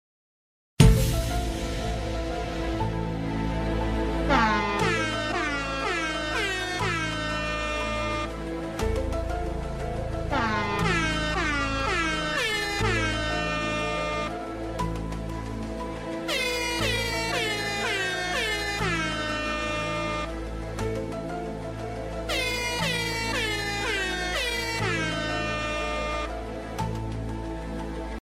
illuminati air horn